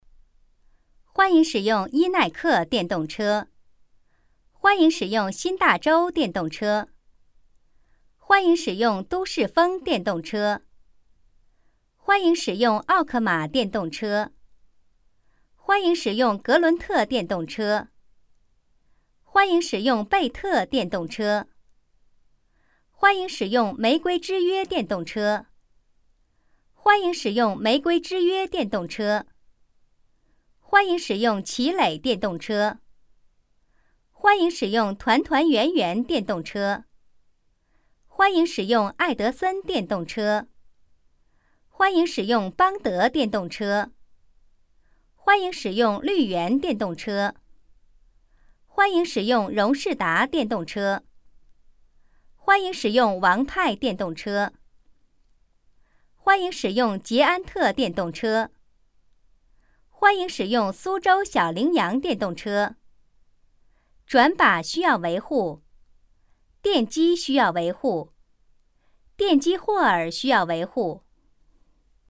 女声配音
提示音女国55A